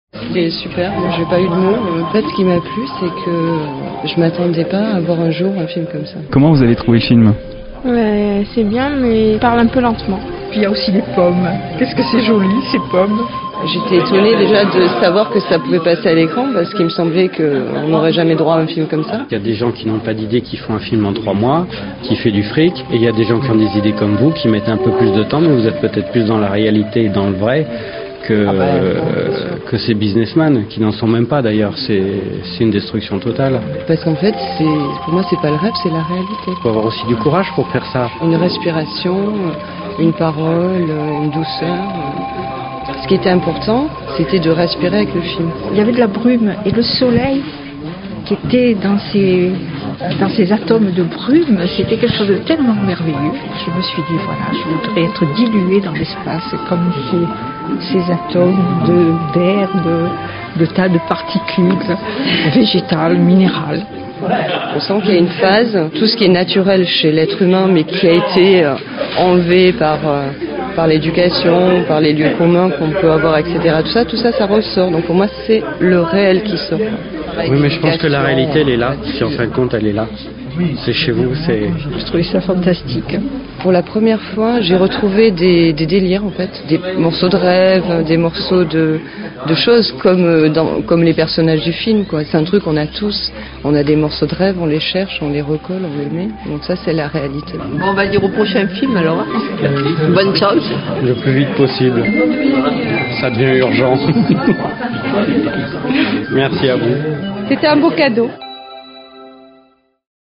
Reportage sur Brignoles : avis et impressions des spectateurs recueillis suite à la projection de Rêve de Lac
ReveDeLac_REPORTAGE_BRIGNOLES.mp3